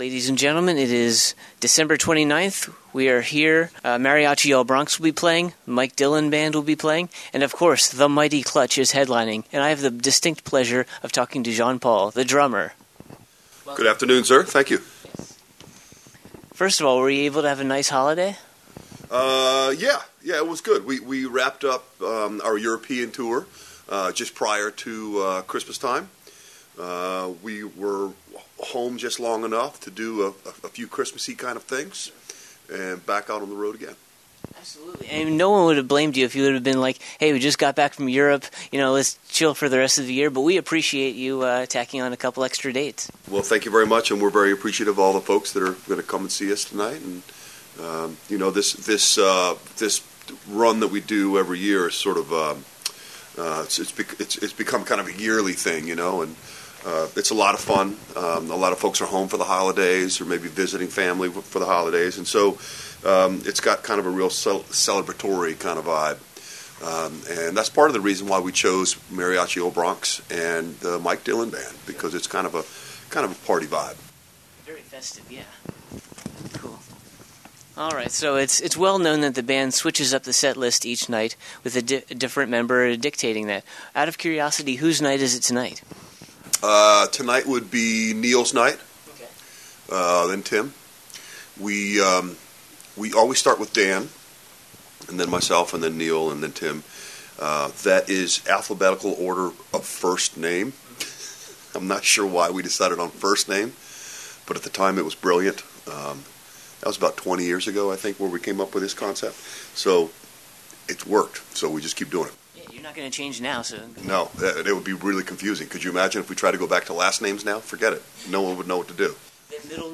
75-interview-clutch.mp3